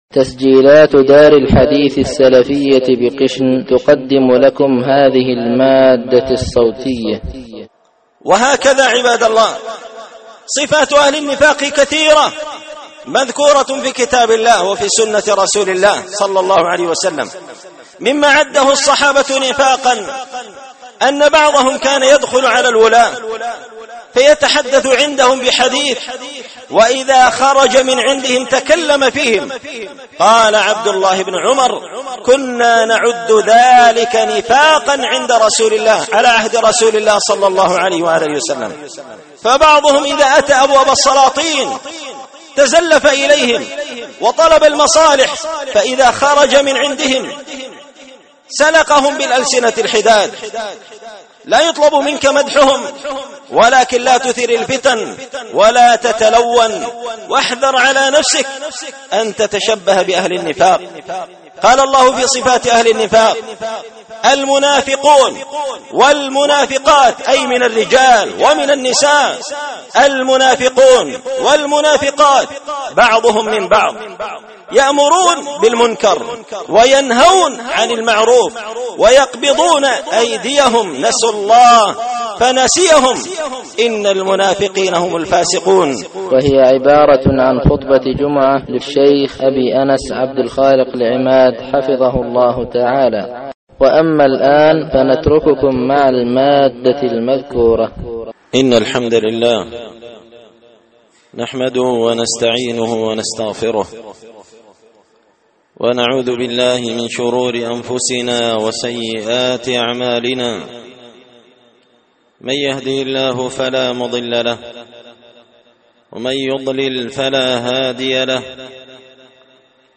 الأربعاء 10 شوال 1443 هــــ | الخطب و المحاضرات | شارك بتعليقك | 15 المشاهدات